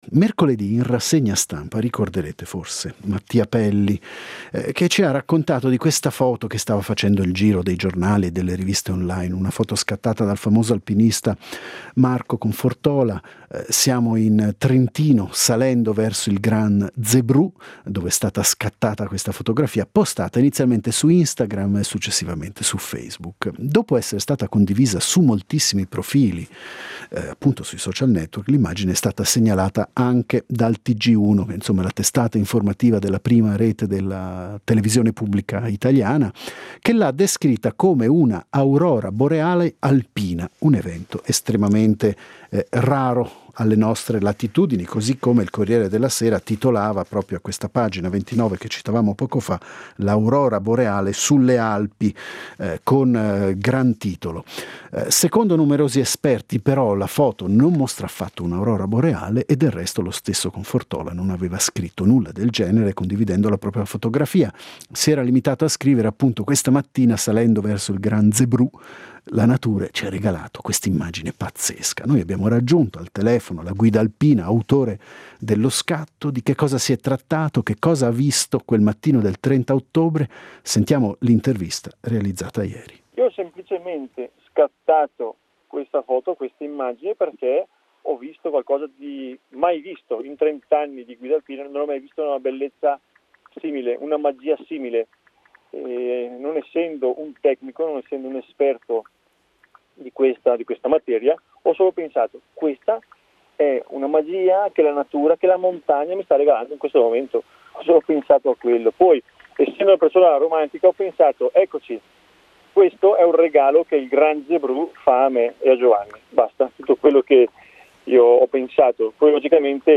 Si chiama Marco Confortola e con lui abbiamo provato a capire che cosa c’è in quello scatto che ha fatto il giro del mondo.